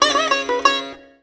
SITAR GRV 12.wav